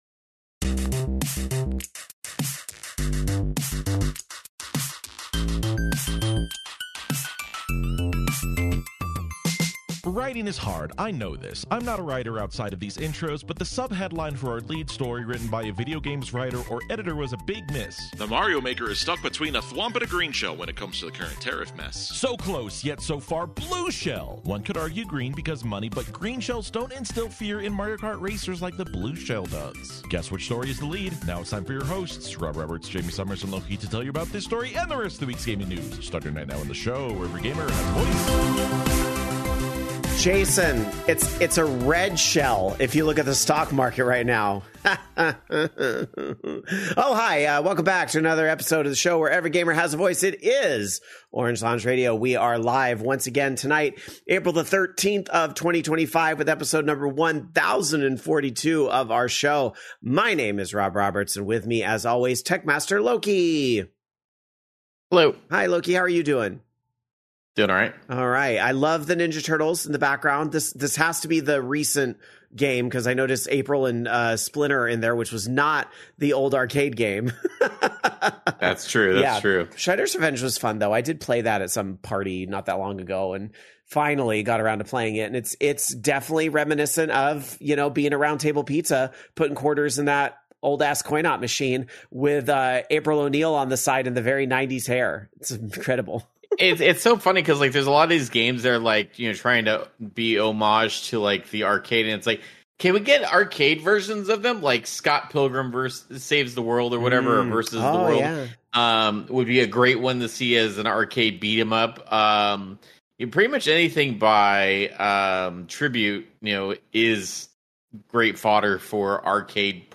We debate this and much more with our live audience on another week of the longest running video game podcast, Orange Lounge Radio!